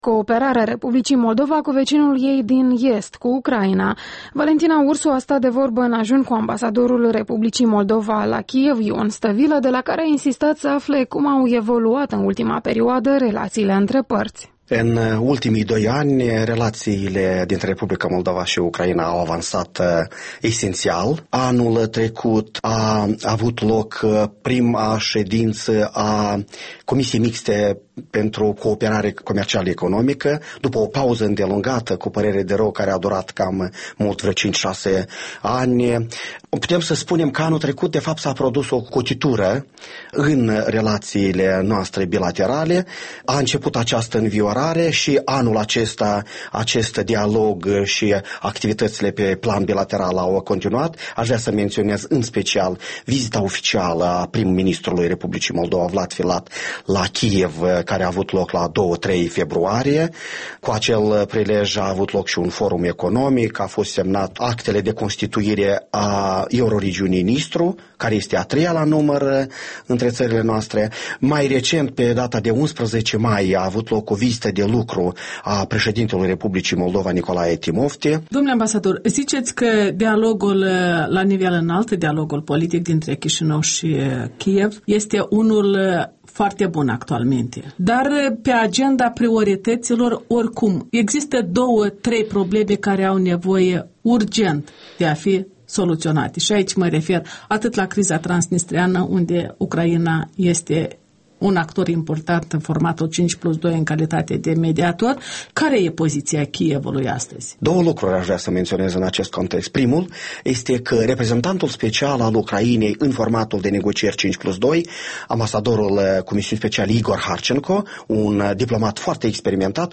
Interviul dimineții la EL: în dialog cu ambasadorul Ion Stavilă despre relațiile moldo-ucrainiene